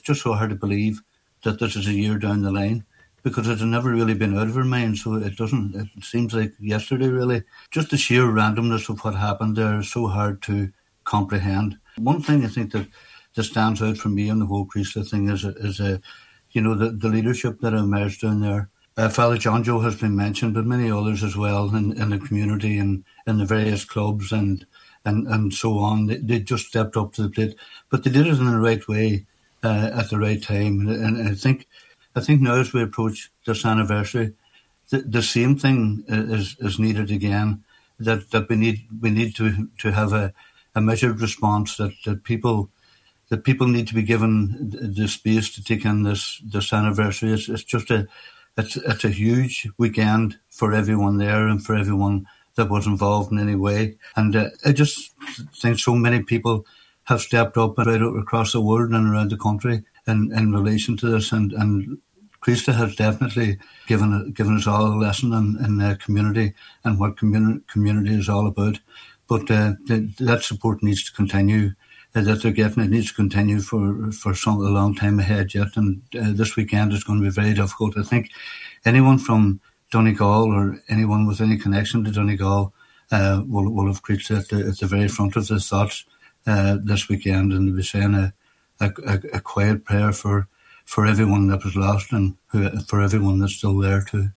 Speaking on today’s Nine til Noon Show, Councillor Kavanagh says it is difficult to comprehend that it is almost a year since 10 people died in the explosion.